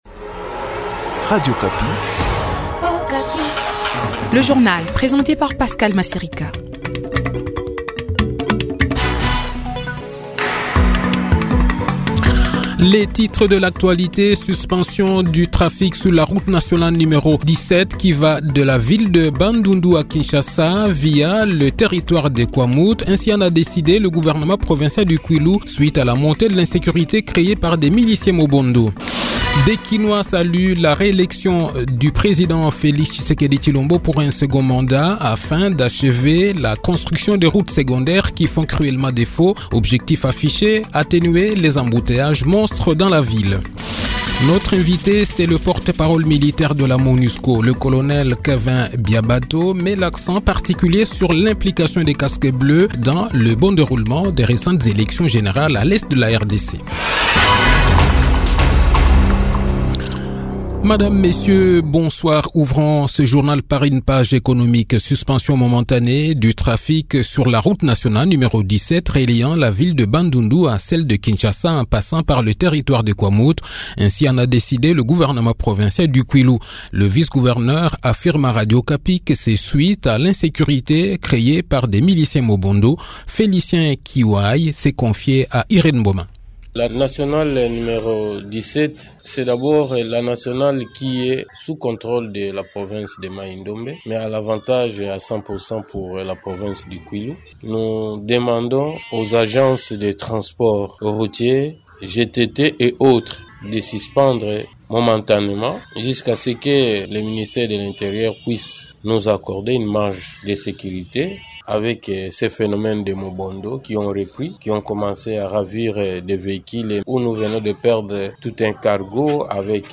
Le journal de 18 h, 2 janvier 2024